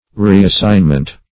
Search Result for " reassignment" : Wordnet 3.0 NOUN (1) 1. assignment to a different duty ; The Collaborative International Dictionary of English v.0.48: Reassignment \Re`as*sign"ment\ (r[=e]`[a^]s*s[imac]n"ment), n. The act of reassigning.